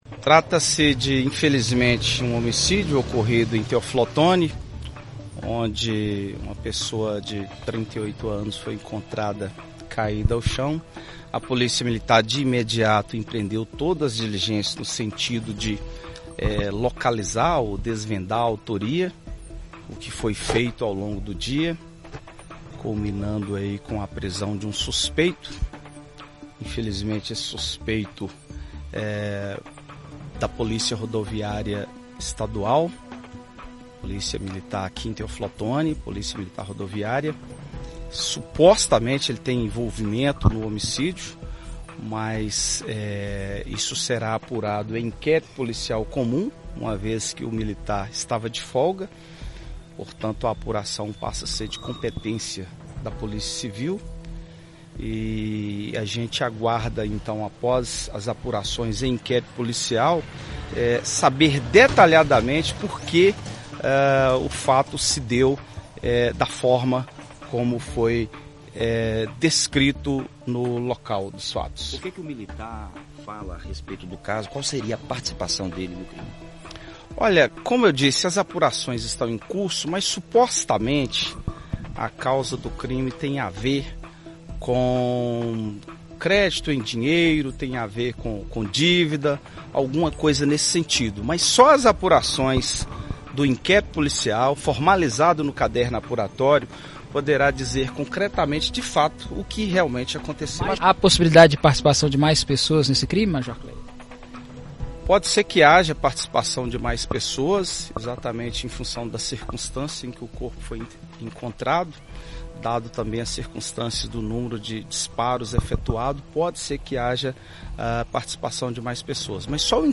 entrevista coletiva